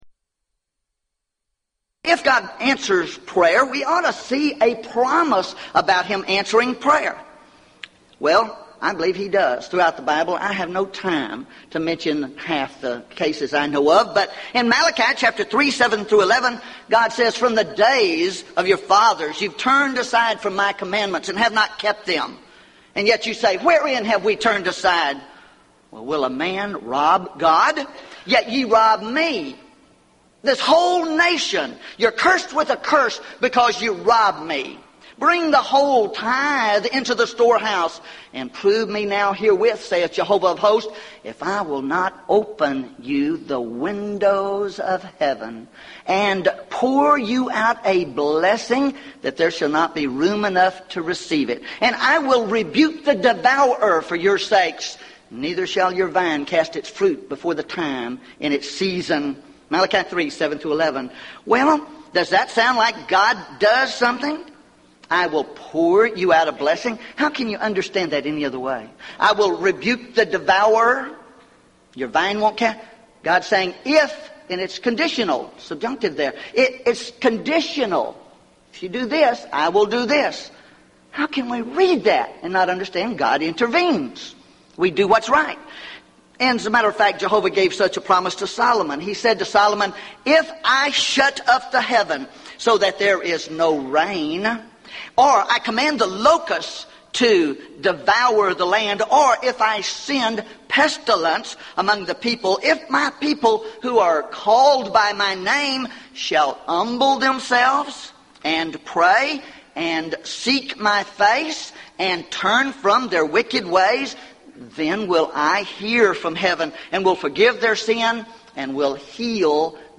Event: 1998 Gulf Coast Lectures Theme/Title: Prayer and Providence